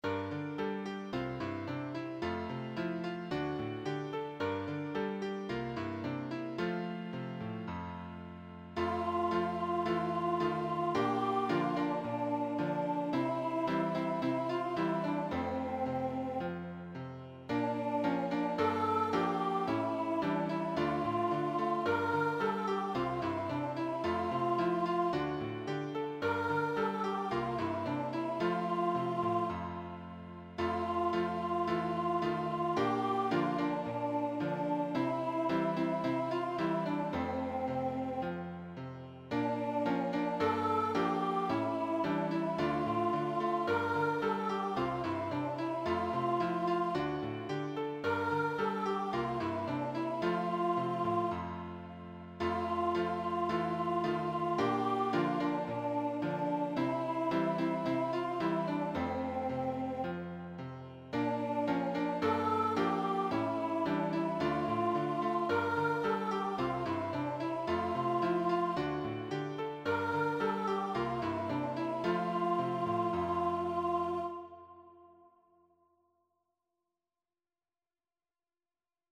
A collection of 15 uplifting and Christ-glorifying children’s choruses covering a wide range of Biblical topics.